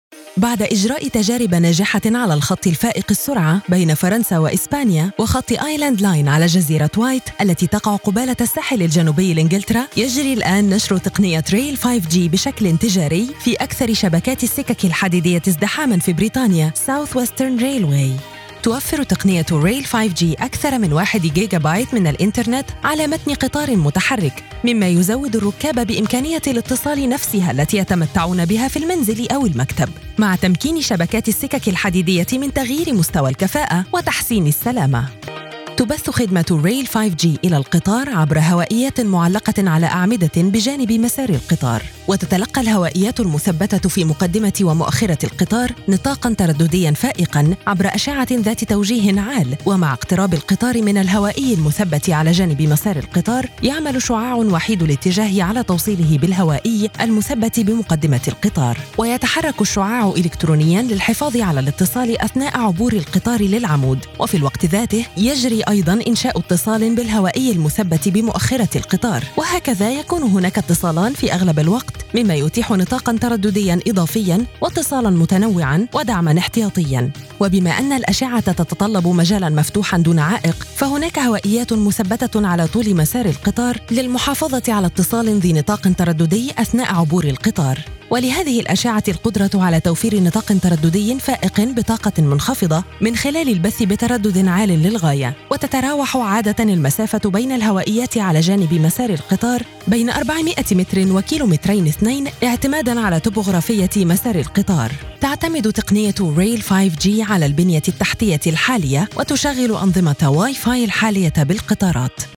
Narration Voice over in Arabic
Voiceover Voice Female Narration Arabic